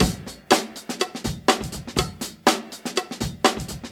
123 Bpm Breakbeat Sample F# Key.wav
Free breakbeat - kick tuned to the F# note. Loudest frequency: 2586Hz
.WAV .MP3 .OGG 0:00 / 0:04 Type Wav Duration 0:04 Size 675,81 KB Samplerate 44100 Hz Bitdepth 16 Channels Stereo Free breakbeat - kick tuned to the F# note.
123-bpm-breakbeat-sample-f-sharp-key-6bt.ogg